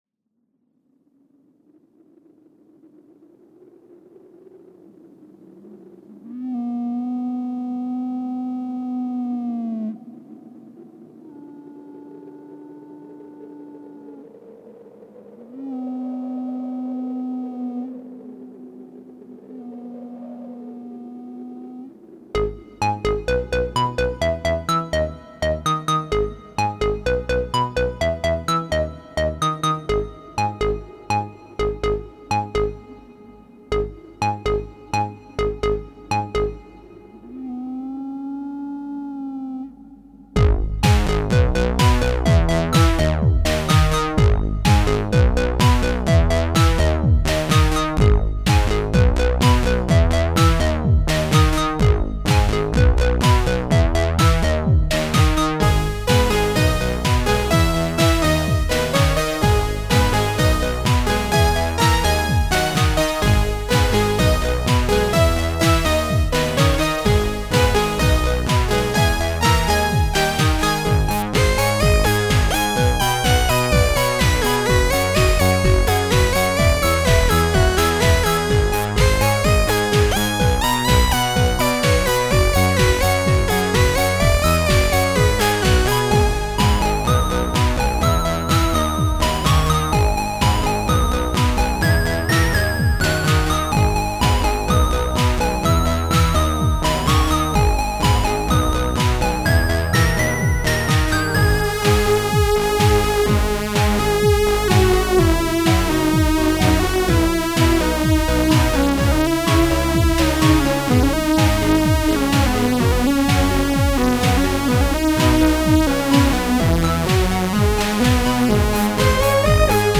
Il a été entièrement composé avec un Polivoks.
Formanta Polivoks.
Quant à « Hot inside », c’est un morceau challenge, car il devait être composé avec un seul synthé (monophonique en plus!).